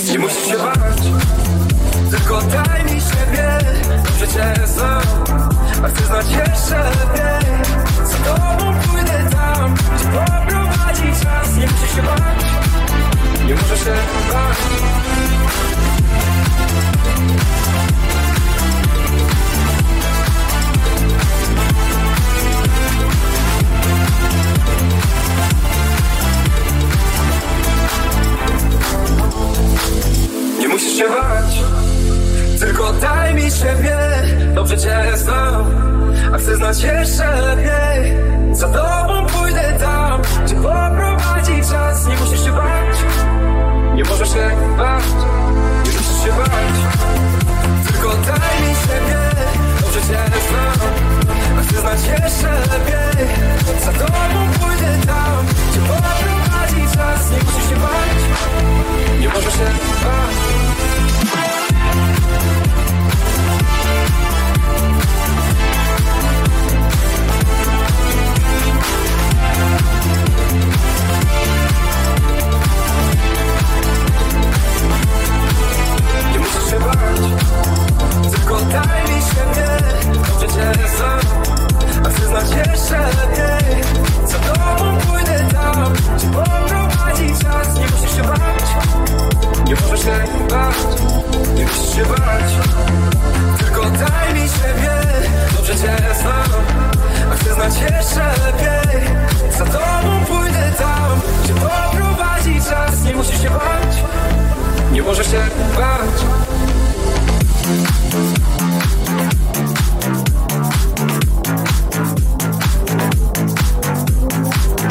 w Disco polo